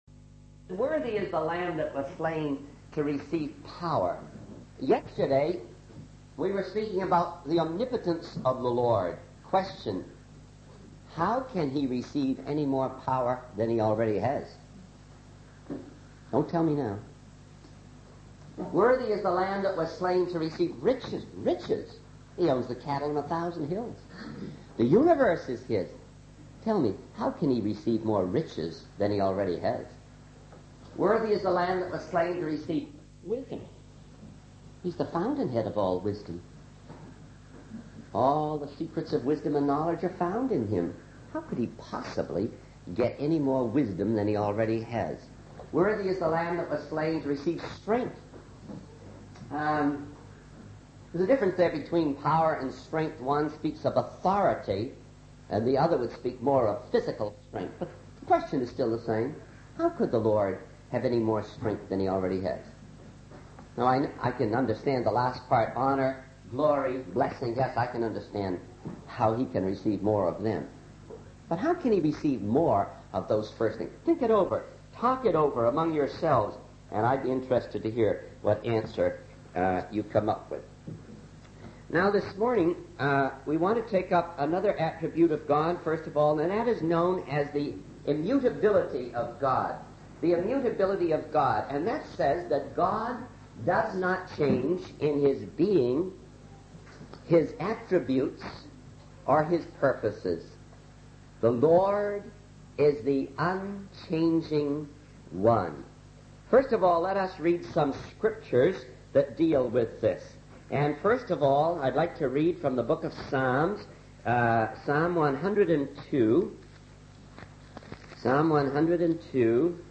In this sermon, the speaker marvels at the complexity and design of the human body, particularly the eyes and the brain.